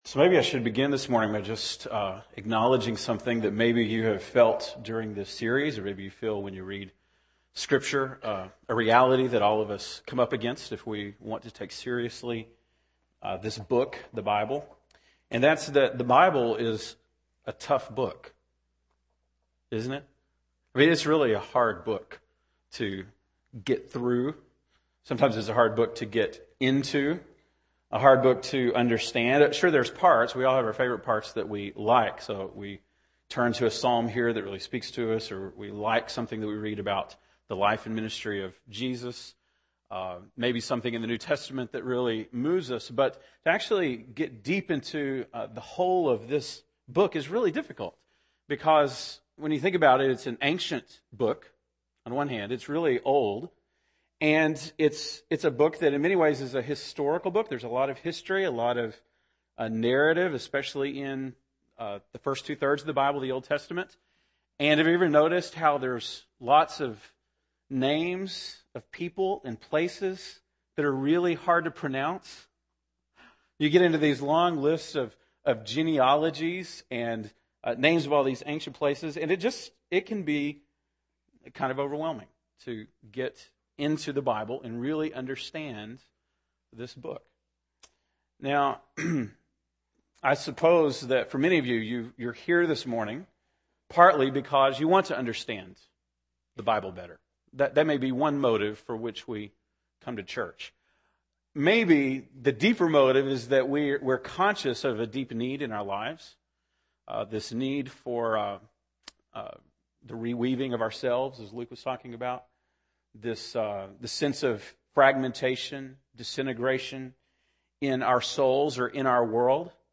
March 14, 2010 (Sunday Morning)